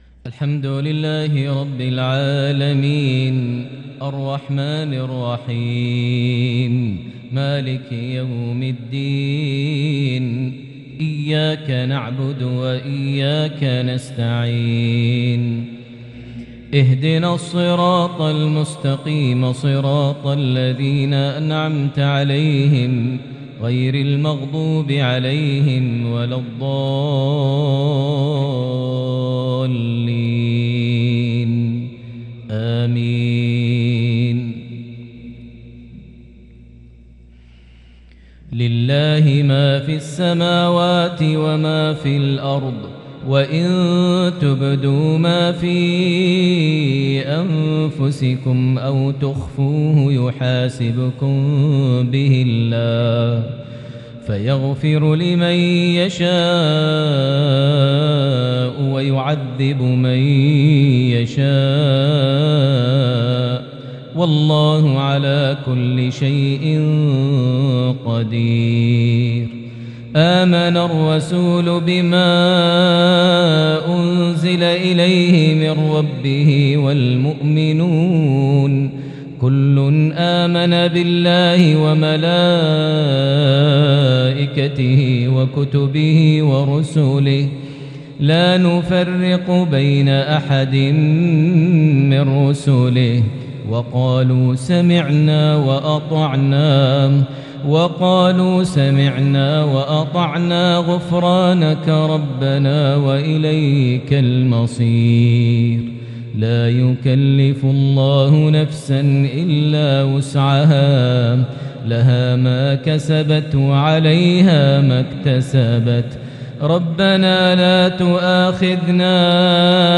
maghrib 5-2-2022 prayer from Surah Al-Baqara + Surah At-Taubah > 1443 H > Prayers - Maher Almuaiqly Recitations